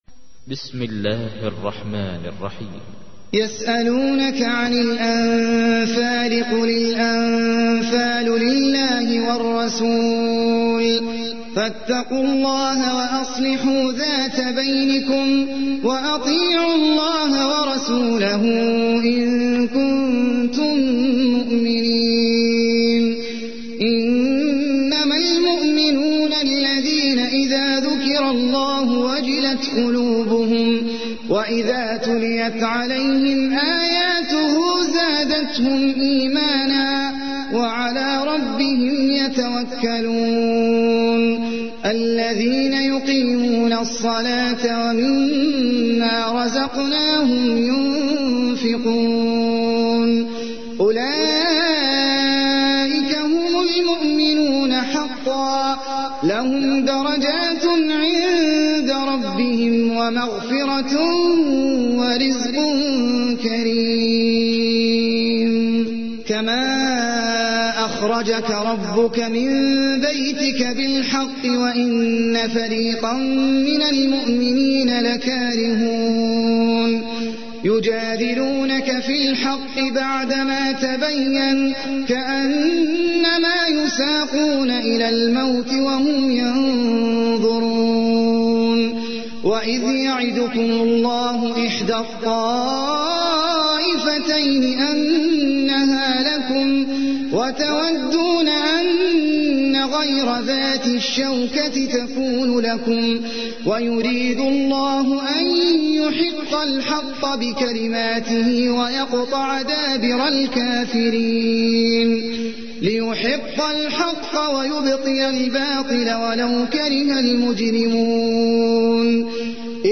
تحميل : 8. سورة الأنفال / القارئ احمد العجمي / القرآن الكريم / موقع يا حسين